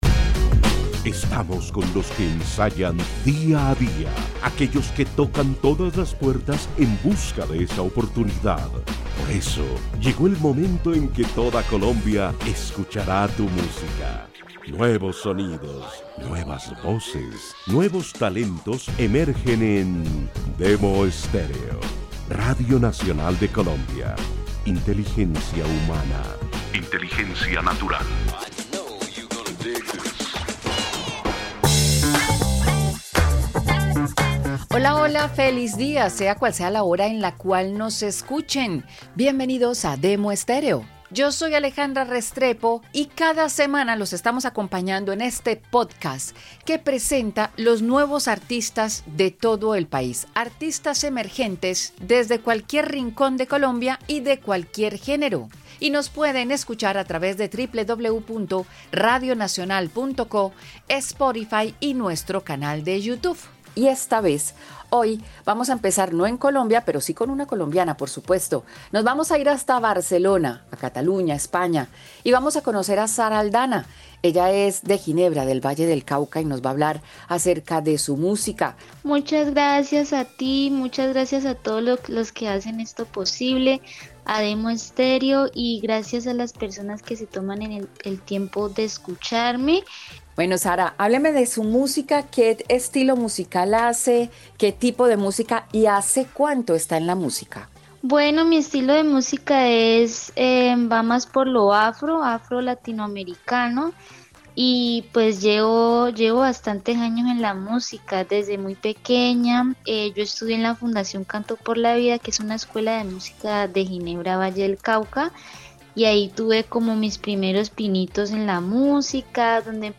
..Demo estéreo pódcast.
una mezcla de tradición, afrocolombianidad y pop.